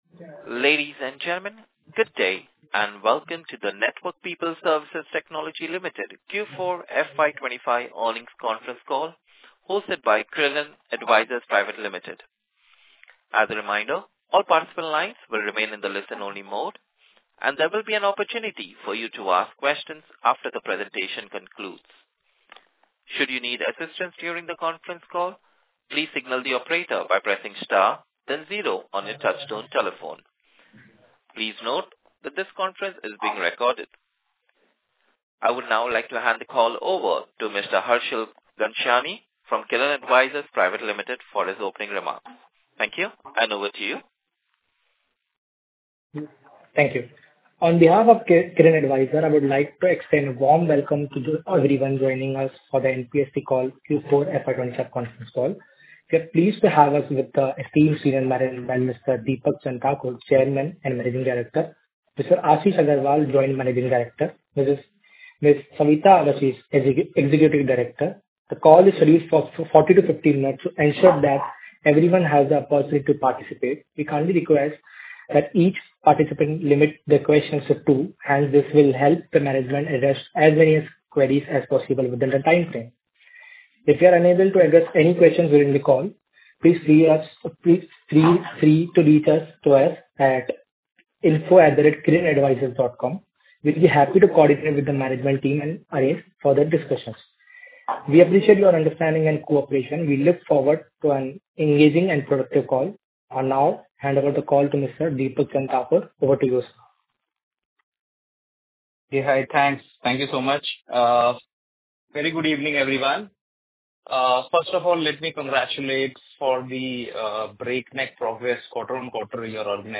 NPST+Q4+FY25+Earnings+concall+audio+recording.mp3